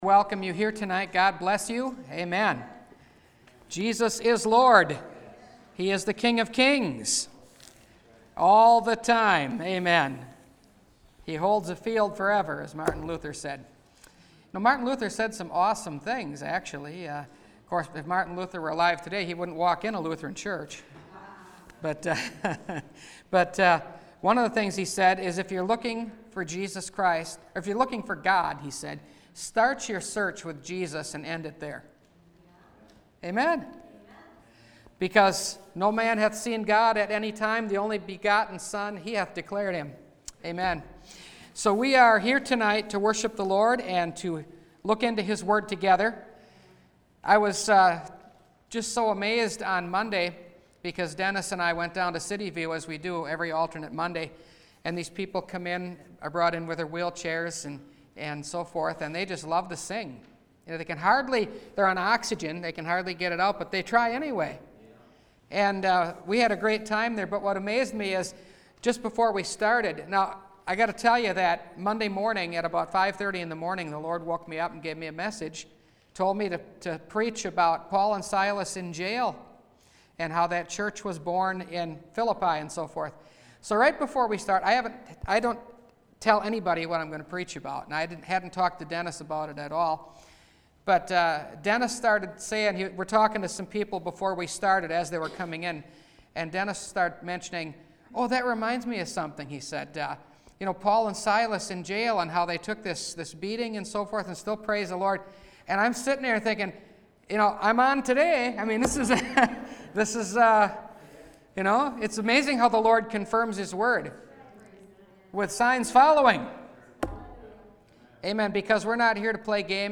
I Was Blind, Now I See – Last Trumpet Ministries – Truth Tabernacle – Sermon Library